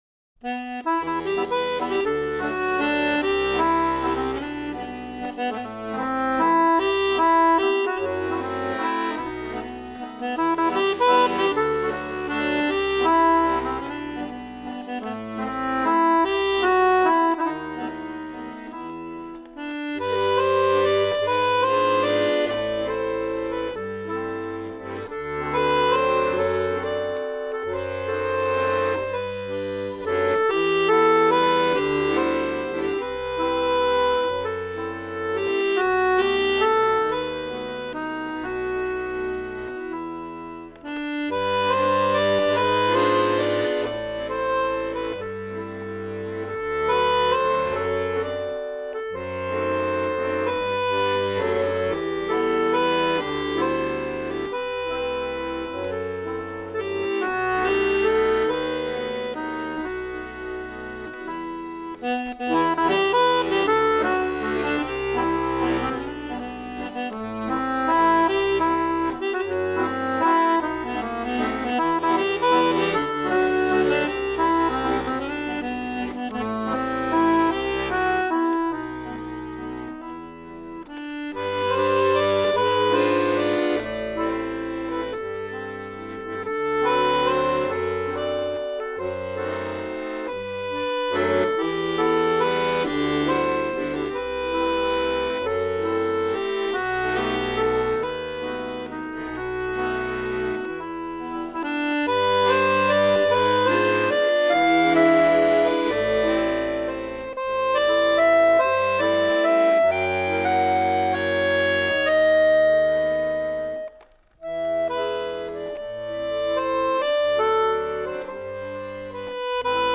dragspel